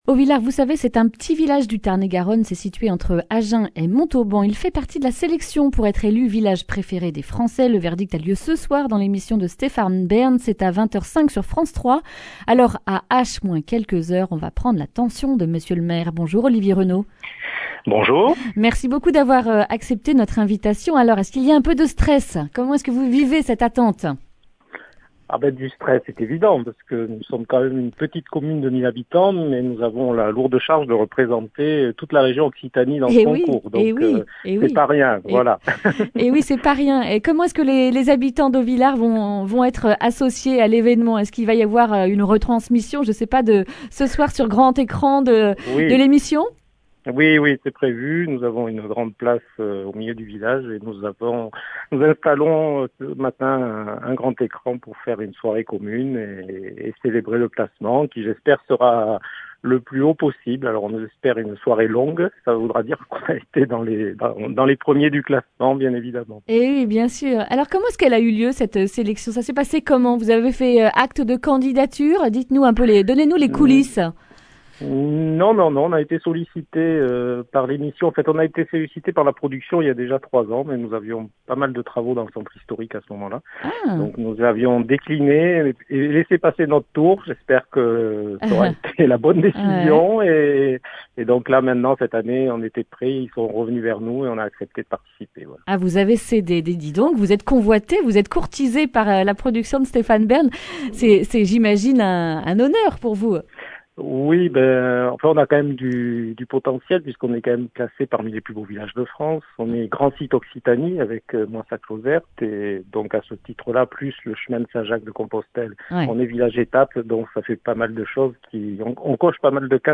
mercredi 30 juin 2021 Le grand entretien Durée 10 min